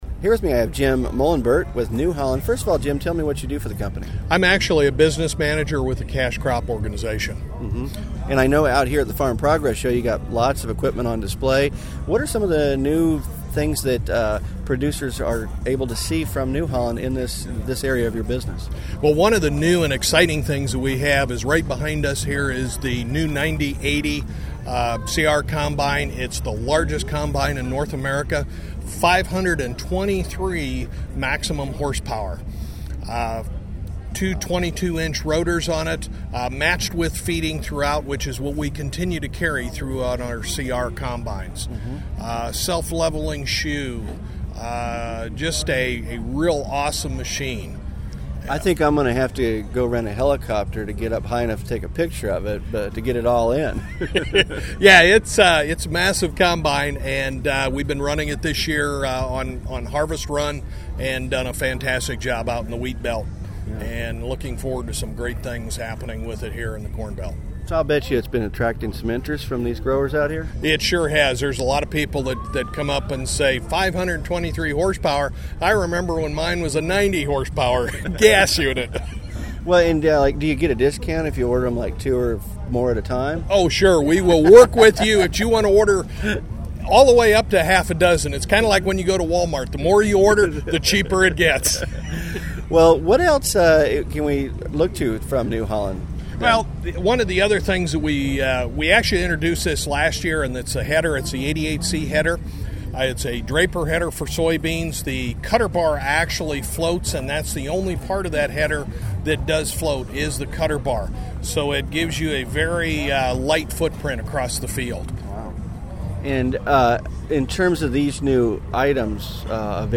AgWired coverage of the 2008 Farm Progress Show